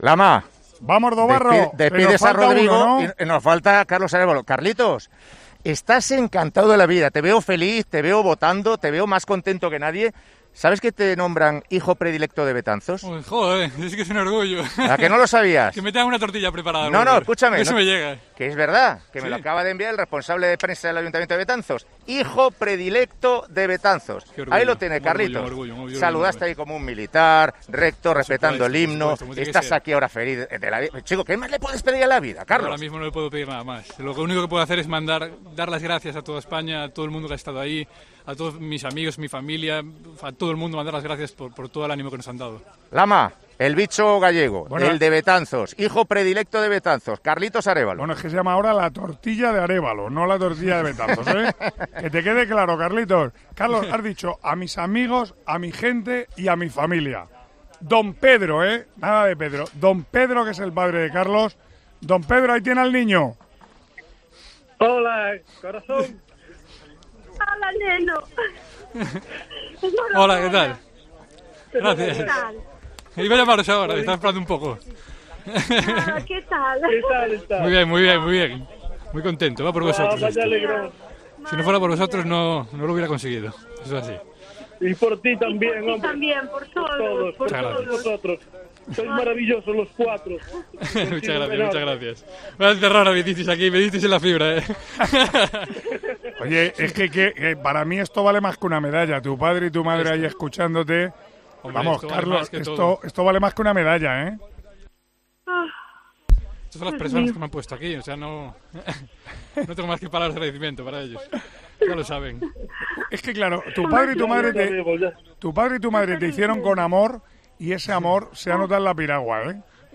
El palista español, integrante del K4 500, ha conseguido la medalla de plata en los Juegos de Tokio y ha recibido la felicitación de sus padres en Tiempo de Juego.